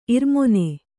♪ irmone